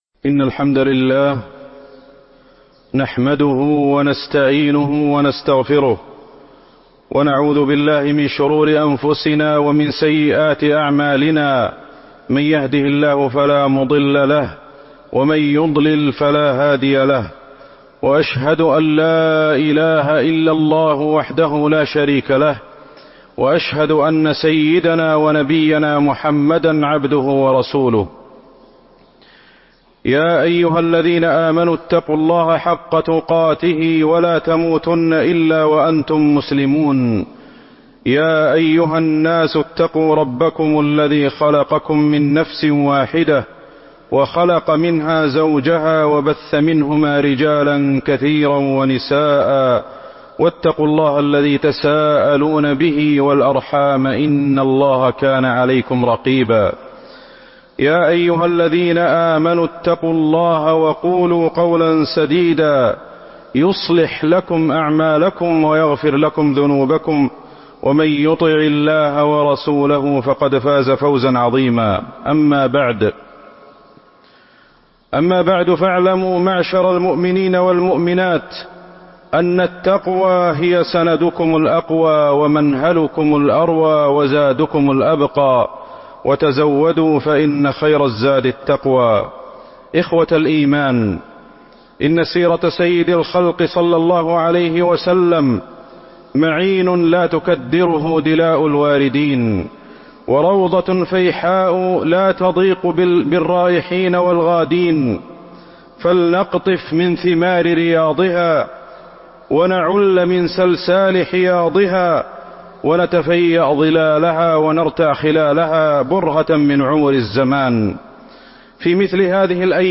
تاريخ النشر ٦ ذو القعدة ١٤٤٤ المكان: المسجد النبوي الشيخ: فضيلة الشيخ أحمد الحذيفي فضيلة الشيخ أحمد الحذيفي صلح الحديبية عبر ودروس